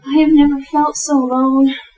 标签： 讲话 谈话 声音 女孩 英语 女性 声音 讲话 美国 谈话 令人毛骨悚然 也就是说 女人
声道立体声